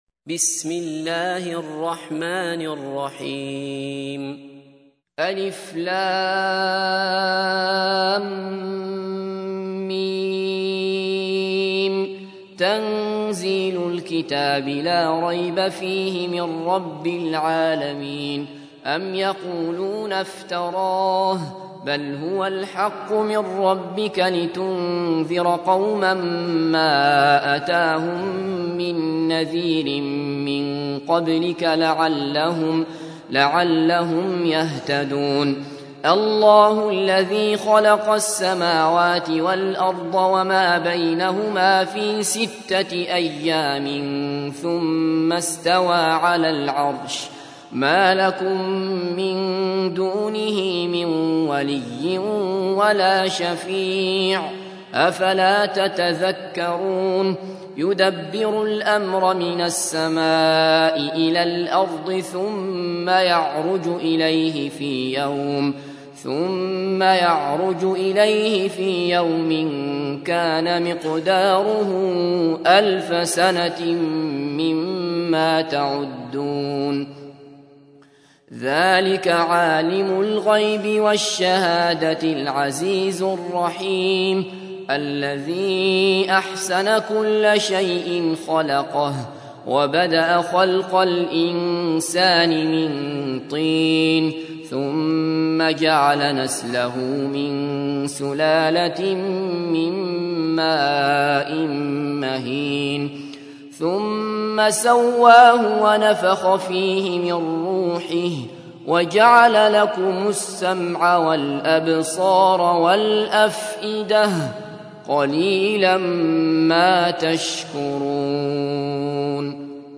تحميل : 32. سورة السجدة / القارئ عبد الله بصفر / القرآن الكريم / موقع يا حسين